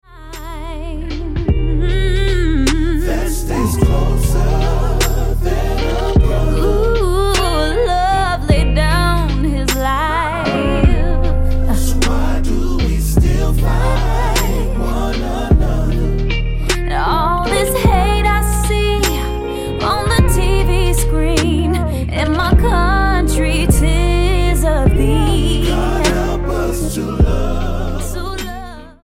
STYLE: Gospel
vocal group
blues-tinged